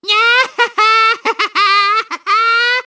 One of Lakitu's voice clips in Mario Kart 7